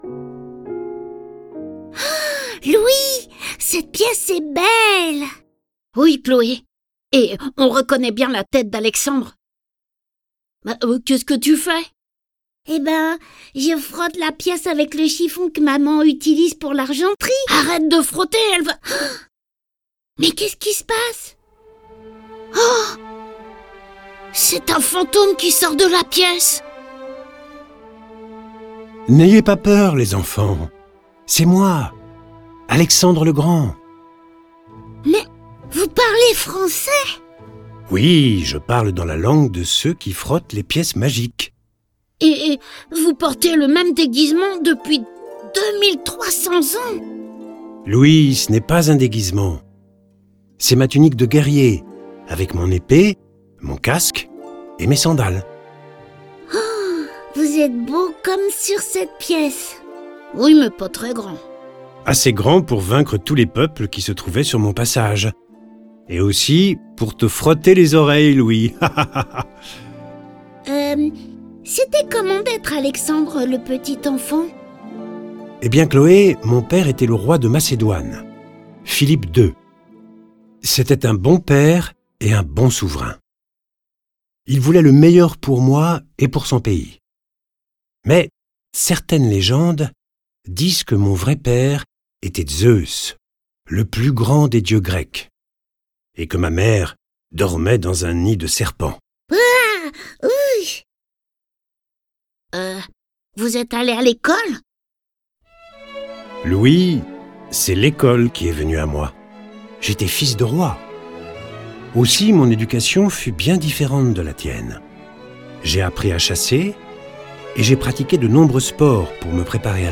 Diffusion distribution ebook et livre audio - Catalogue livres numériques
En une décennie, il devient l'un des plus grands conquérants de l'histoire en s'emparant de l'immense empire perse et en s'avançant jusqu'en Inde. Le récit de la vie du grand guerrier est animé par 6 voix et accompagné de 30 morceaux de musique classique et traditionnelle.